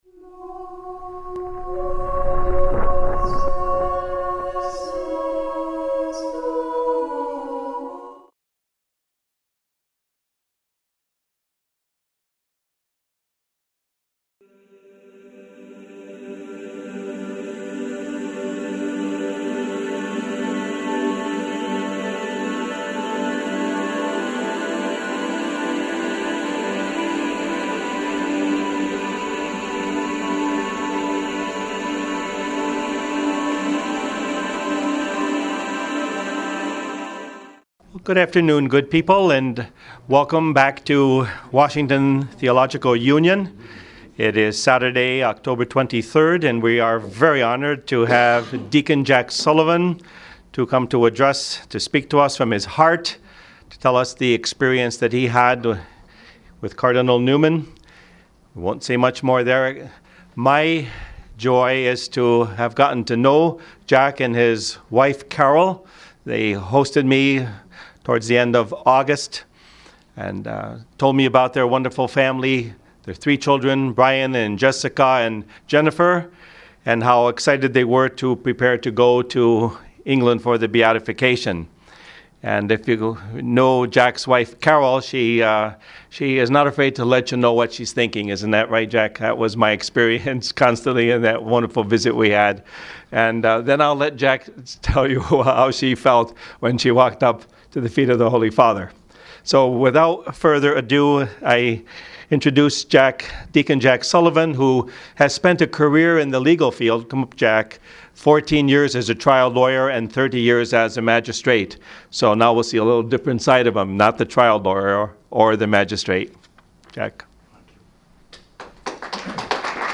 Witness Lecture